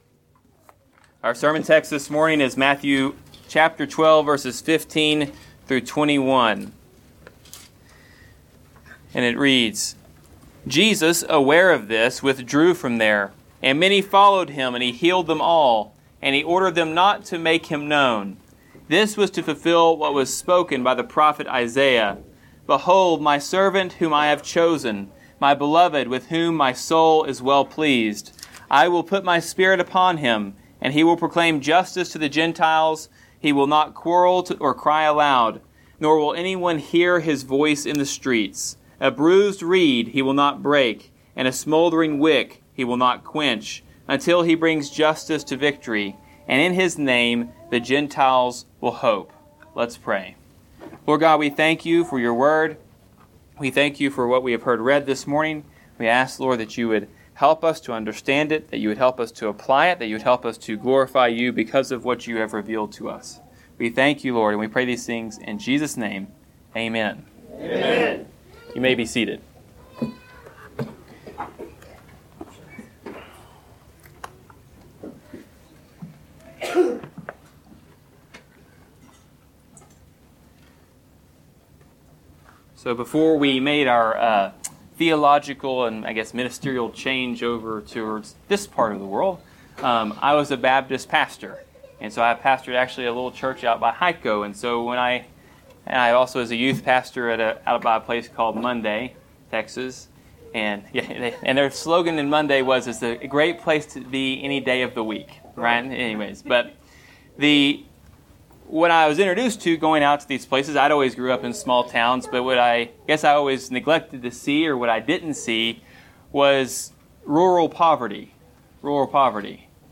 Passage: Matthew 12:15-21 Service Type: Sunday Sermon